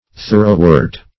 Thoroughwort \Thor"ough*wort`\, n.